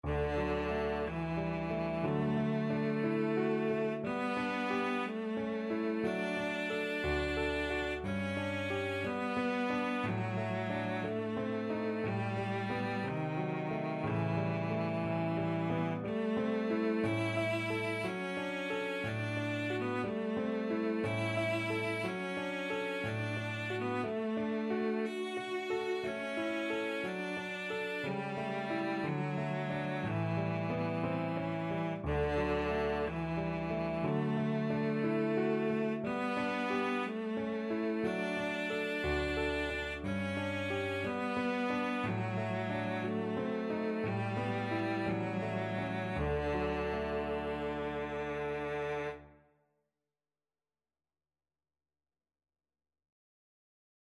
2/4 (View more 2/4 Music)
~ = 60 Andantino (View more music marked Andantino)
Classical (View more Classical Cello Music)